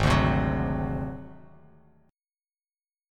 G#7b5 chord